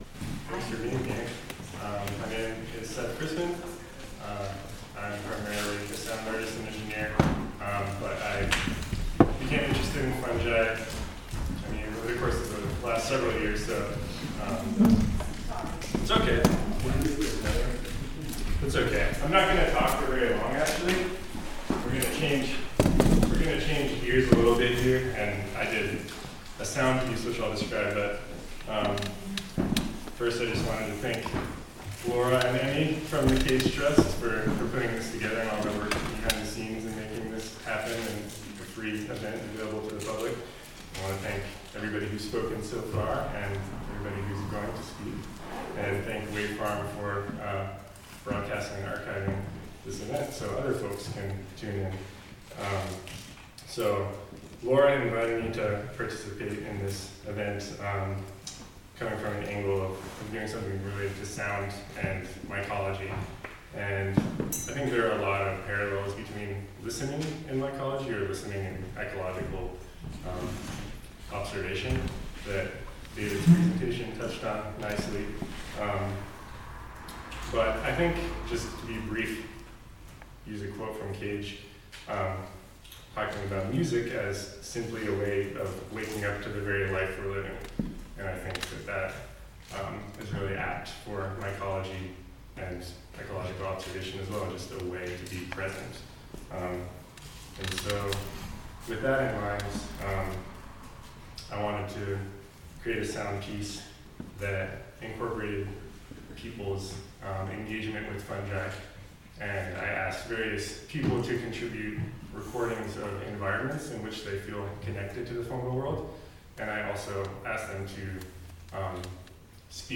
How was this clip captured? A live stream presented in partnership with the Jo...